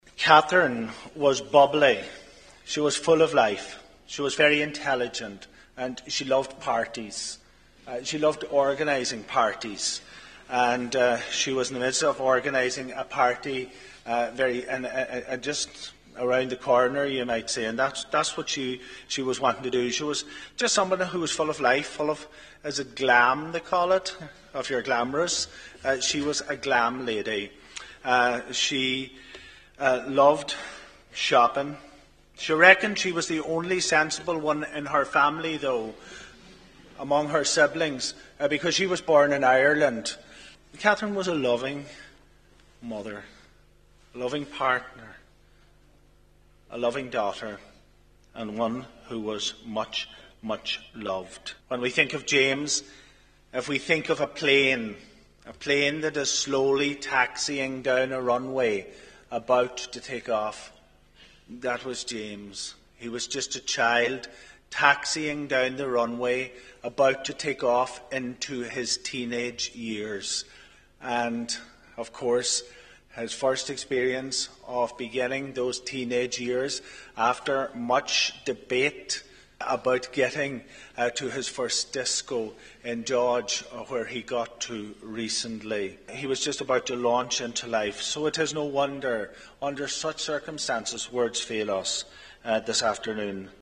Speaking to the congregation